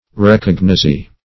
Search Result for " recognizee" : The Collaborative International Dictionary of English v.0.48: Recognizee \Re*cog`ni*zee"\ (r[-e]*k[o^]g`n[i^]*z[=e]" or r[-e]*k[o^]n`[i^]*z[=e]"), n. (Law) The person in whose favor a recognizance is made.